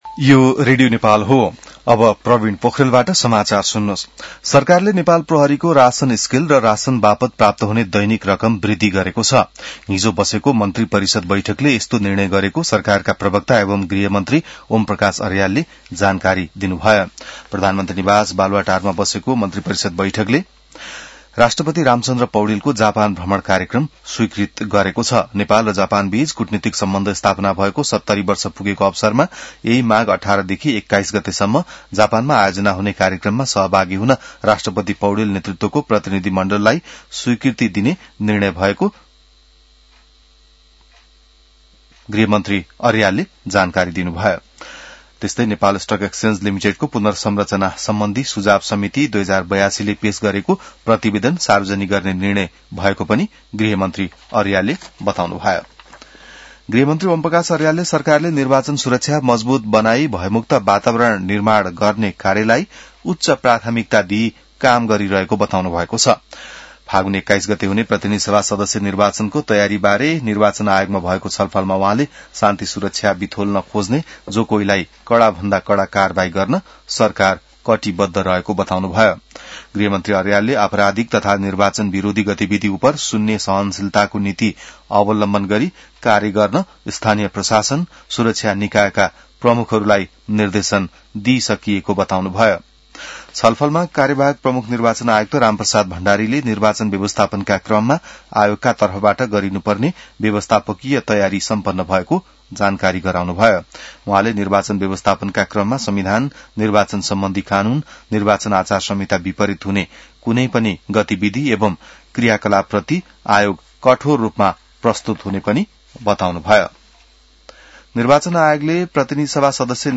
बिहान ६ बजेको नेपाली समाचार : १६ माघ , २०८२